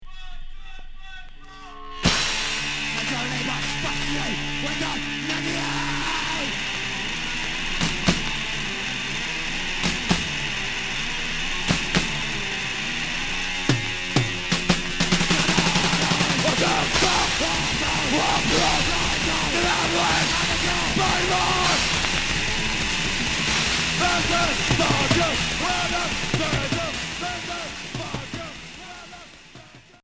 Hardcore Deuxième 45t